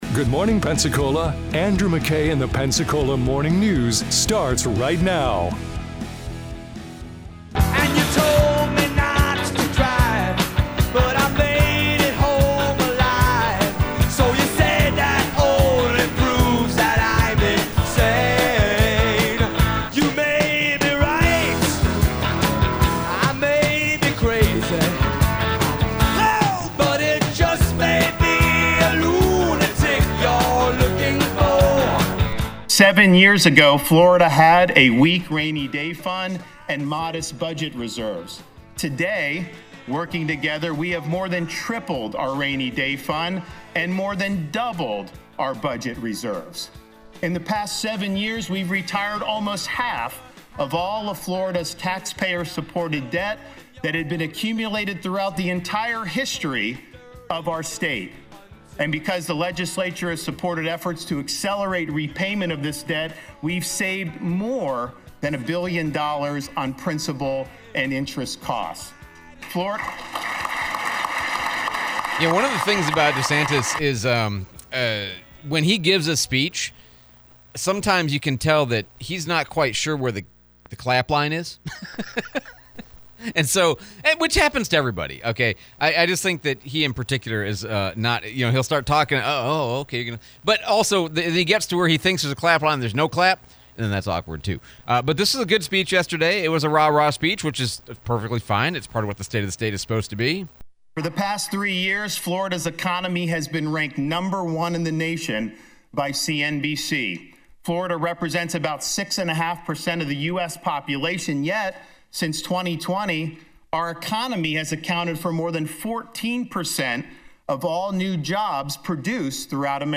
State of Florida Speech, replay of Escambia County Sheriff Chip Simmons